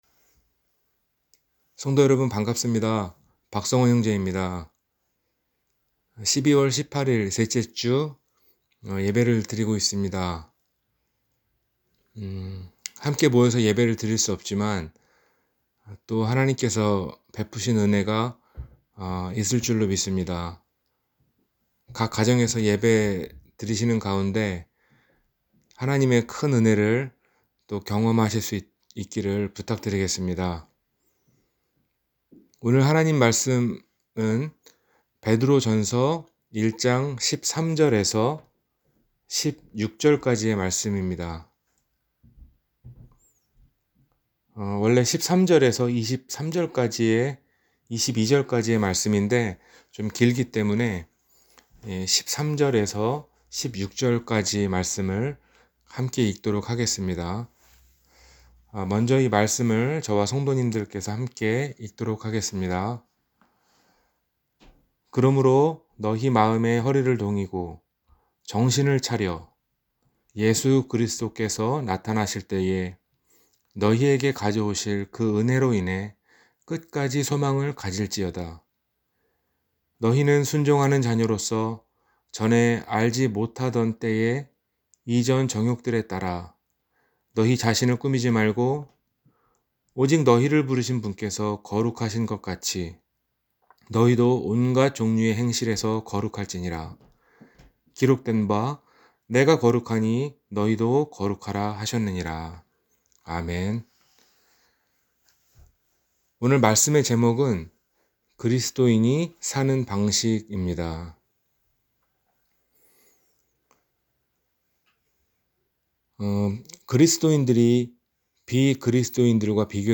그리스도인이 사는 방식-주일설교